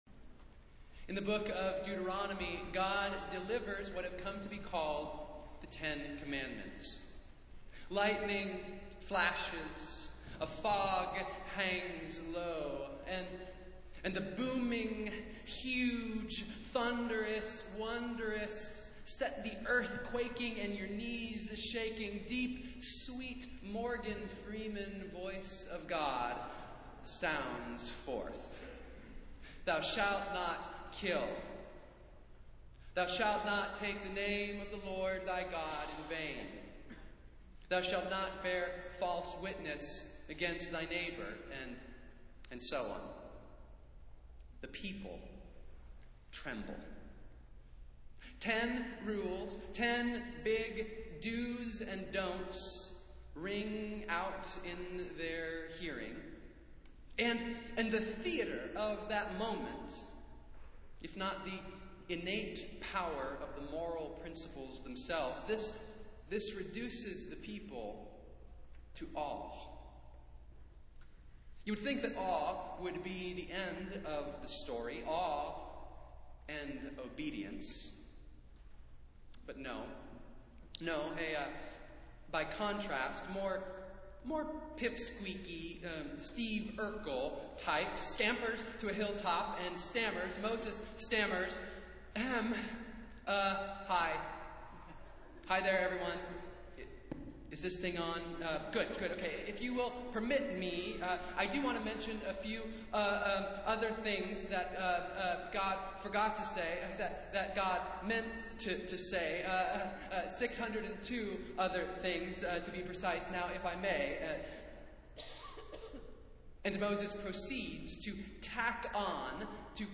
Festival Worship - First Sunday in Advent